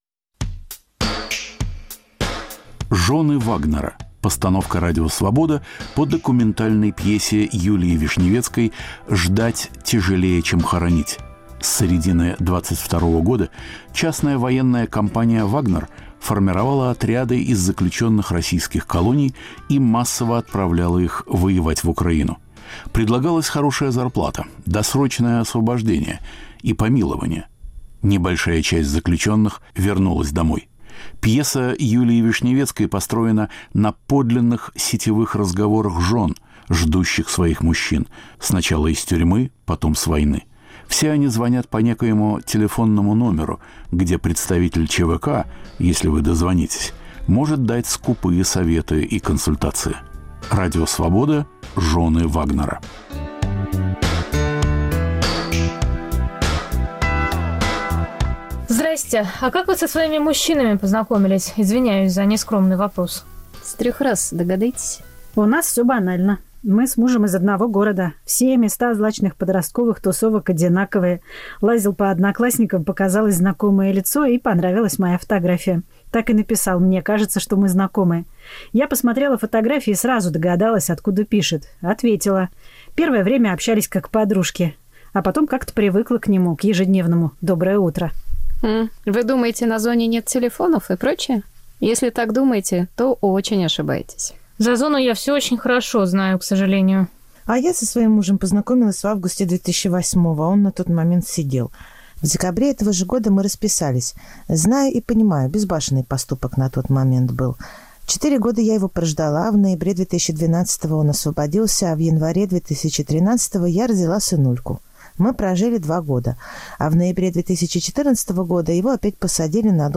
Документальная пьеса о реальности войны